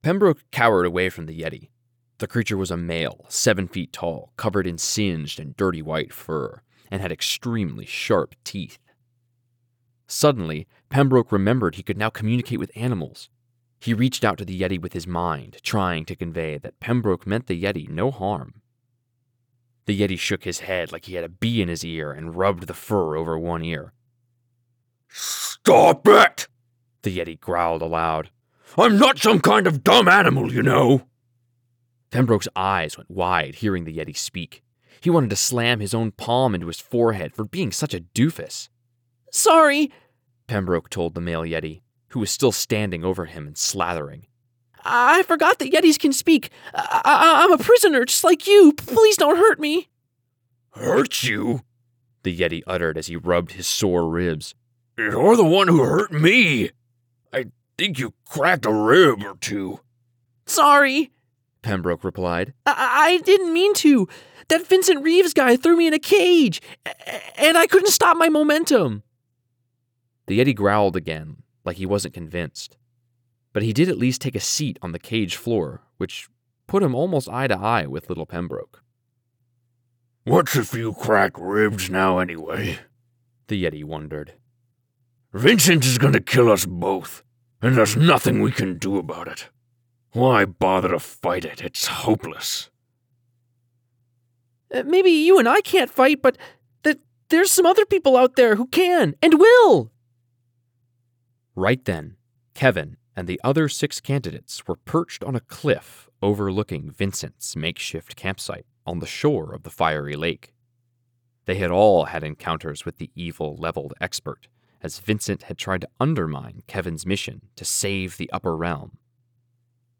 Authentic, Conversational, Executive, and Robust.
Audition for A PocketFM Audio Drama
Male, 18-35.